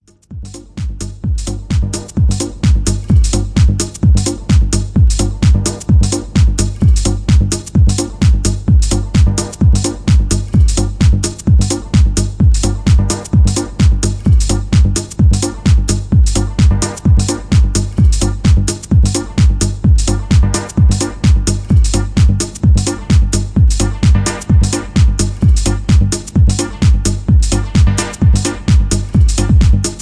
Positive dynamic techno house track